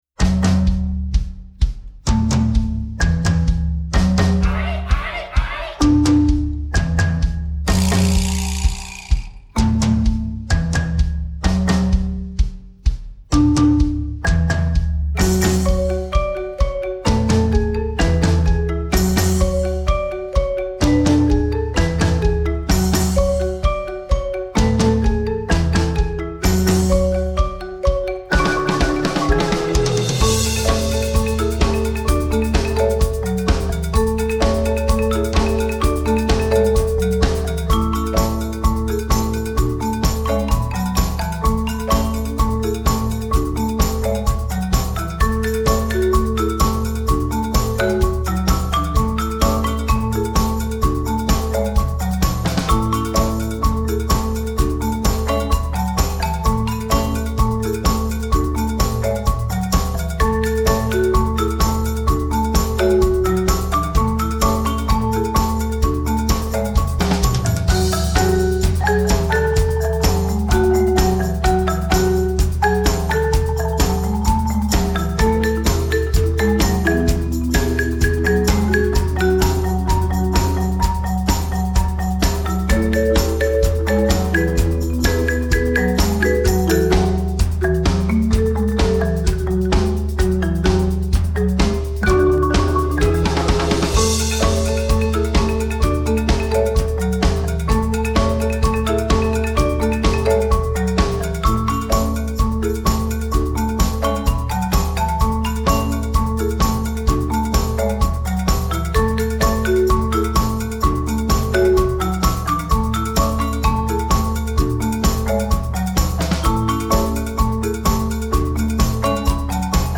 Instrumentalnoten für Schlagzeug/Percussion